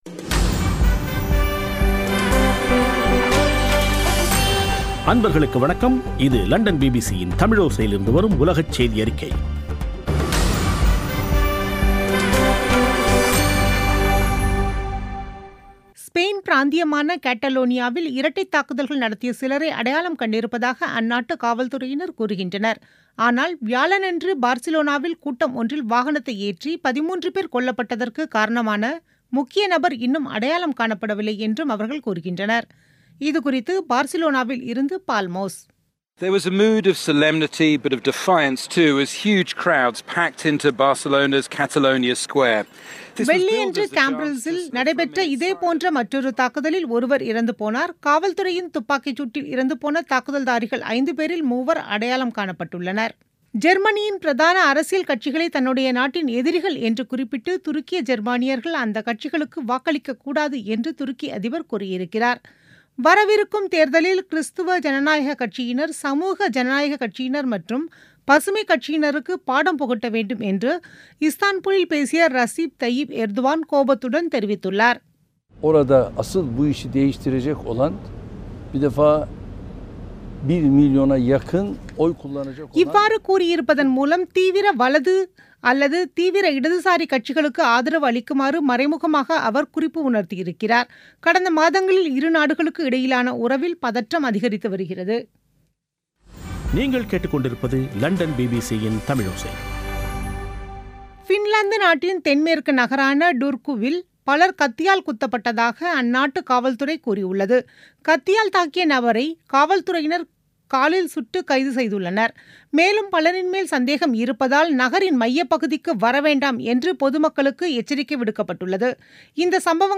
பிபிசி தமிழோசை செய்தியறிக்கை (18/08/2017)